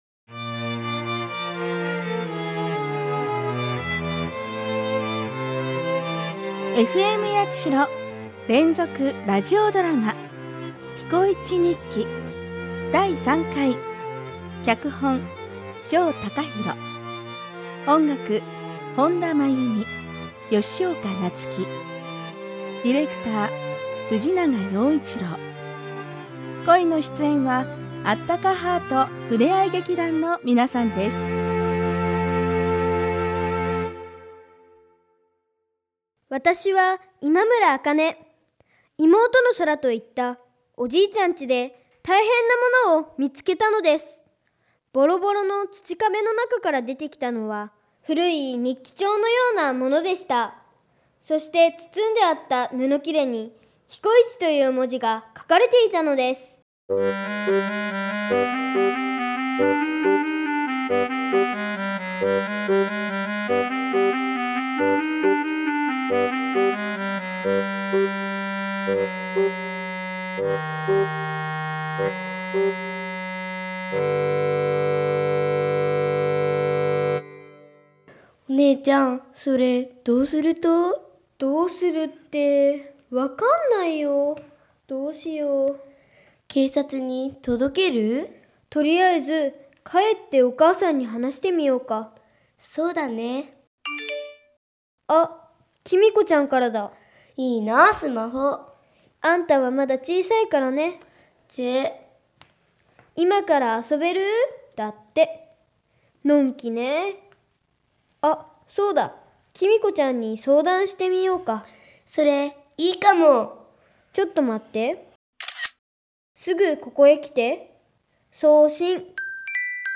Radio Drama